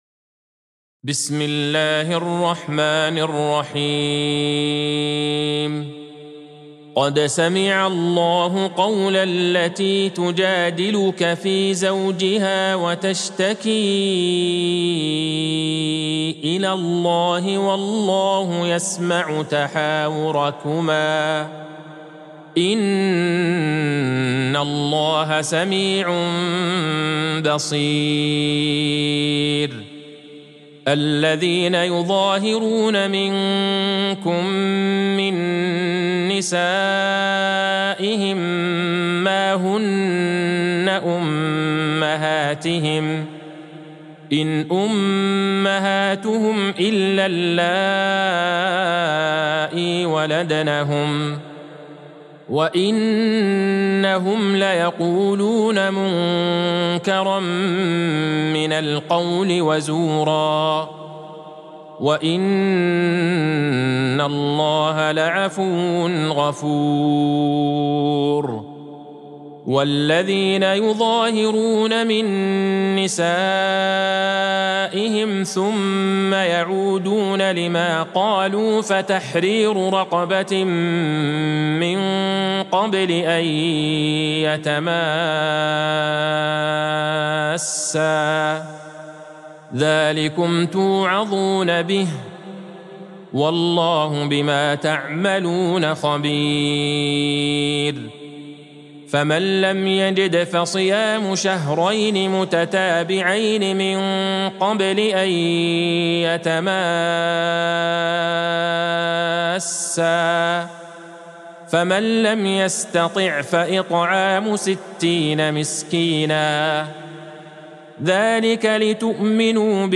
سورة المجادلة Surat Al-Mujadilah | مصحف المقارئ القرآنية > الختمة المرتلة ( مصحف المقارئ القرآنية) للشيخ عبدالله البعيجان > المصحف - تلاوات الحرمين